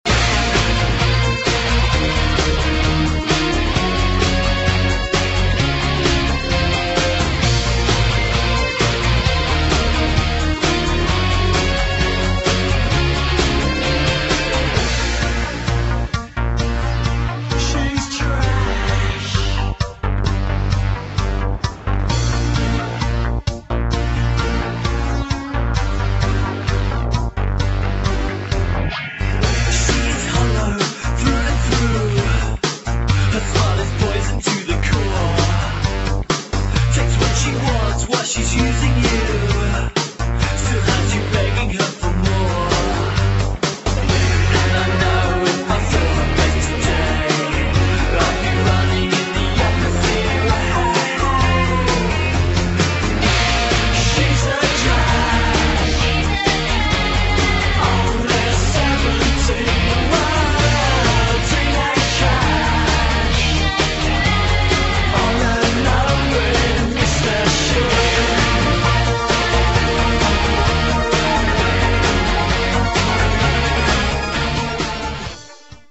[ HOUSE / ELECTRO POP ]